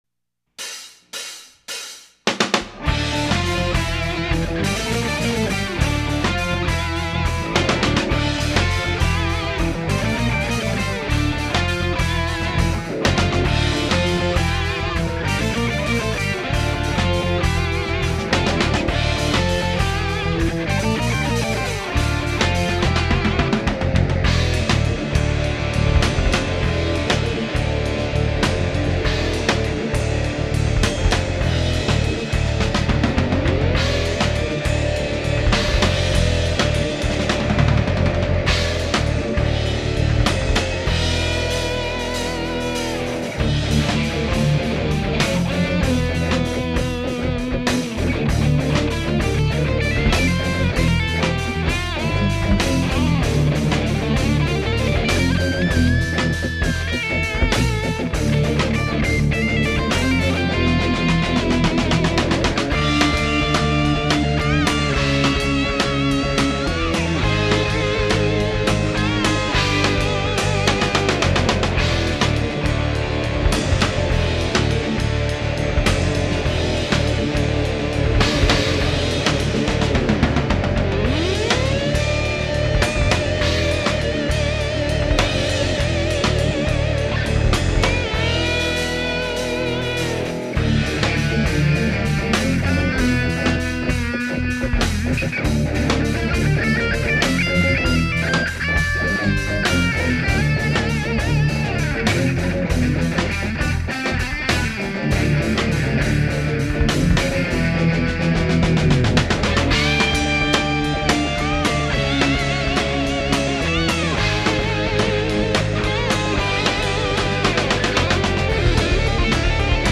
Santa Cruz home studio recordings  (1999)
3 guitars, bass and drums